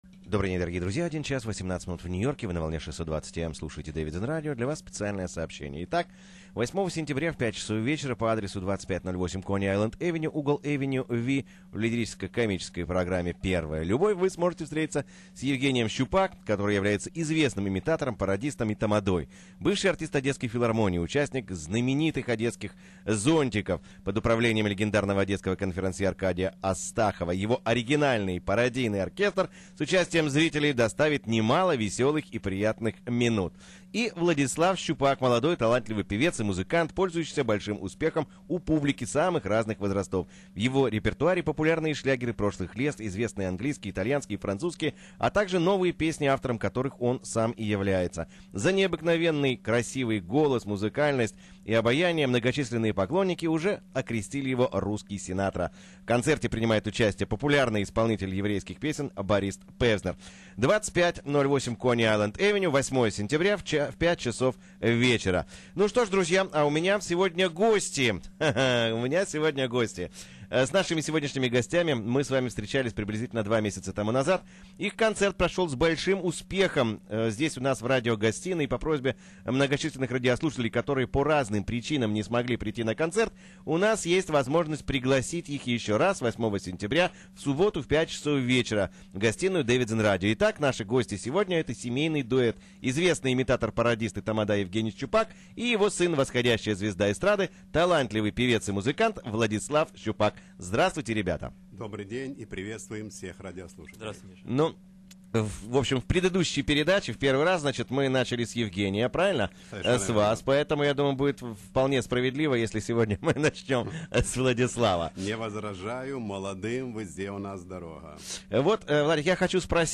Радио Интервью